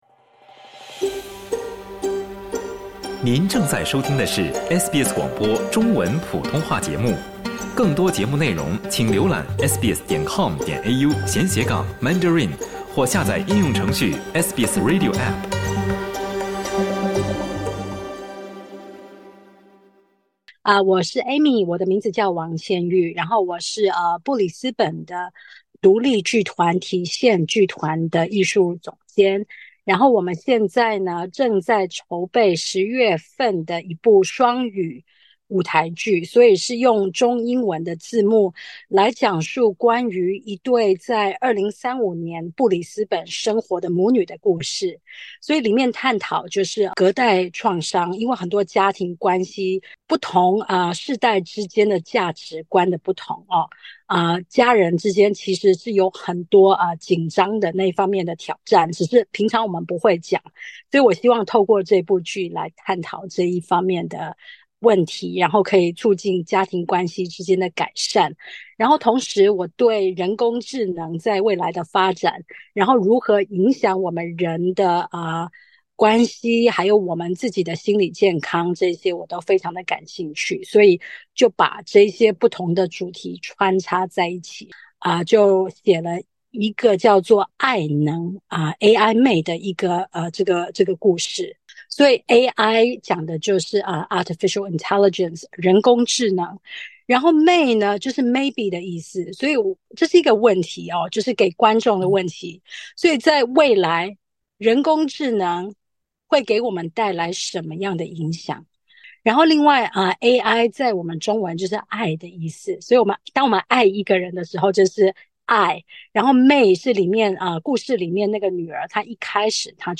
请点击收听采访： LISTEN TO “如何与悲伤共存？”